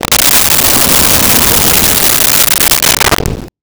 Wind Howl 04
Wind Howl 04.wav